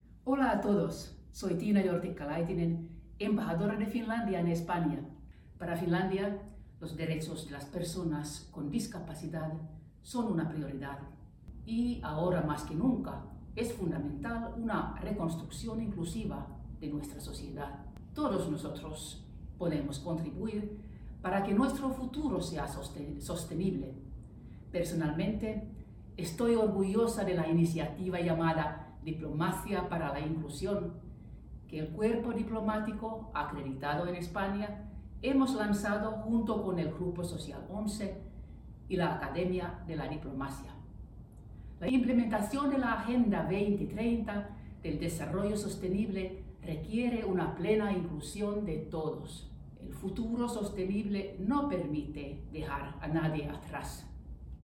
Tiina Jortikka-Laitinen, embajadora de Finlandia en España, también ha mostrado su entusiasmo por la celebración de esta Cumbre Mundial de Personas Ciegas,